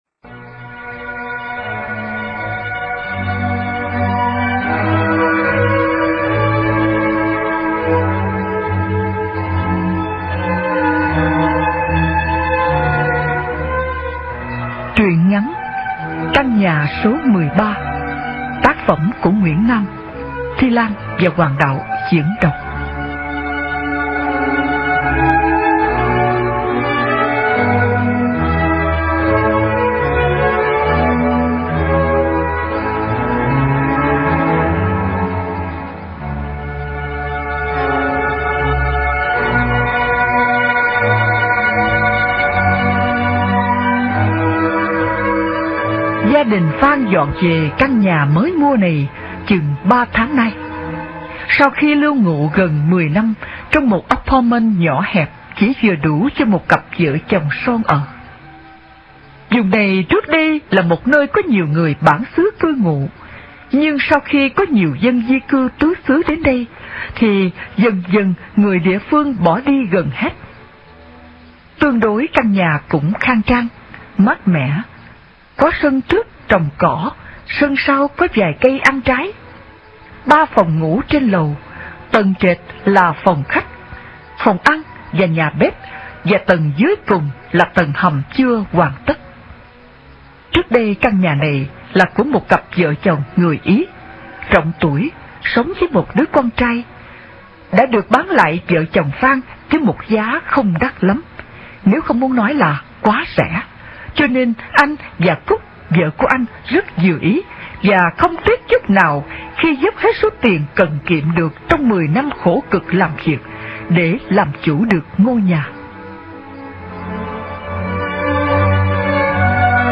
Truyện Ma Audio